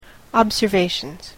/ˌɑbzɝˈveʃʌnz(米国英語), ˌɑ:bzɜ:ˈveɪʃʌnz(英国英語)/